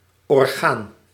Ääntäminen
Synonyymit élément partie composant organisme Ääntäminen France: IPA: /ɔʁ.ɡan/ Haettu sana löytyi näillä lähdekielillä: ranska Käännös Ääninäyte Substantiivit 1. orgaan {n} Muut/tuntemattomat 2. deel {n} Suku: m .